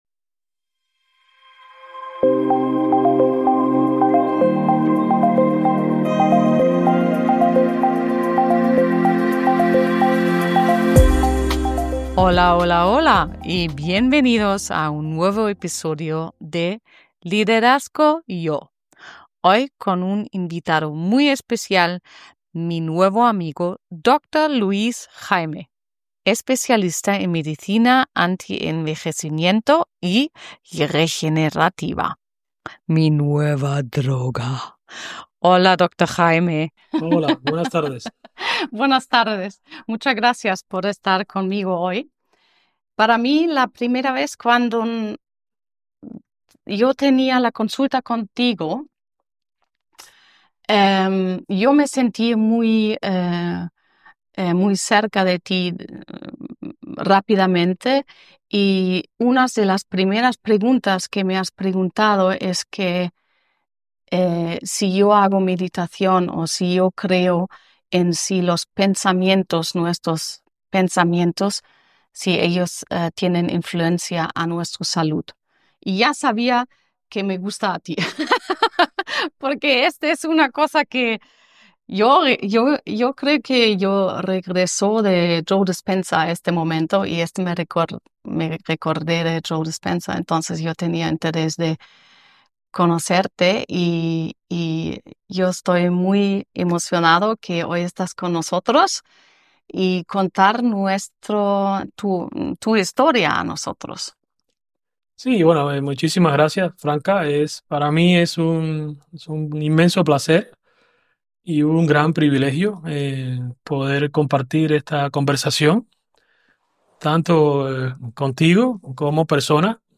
En la entrevista
Un diálogo profundo y hermoso, con una conclusión luminosa: Las cosas más sencillas suelen ser las que generan las soluciones más impresionantes.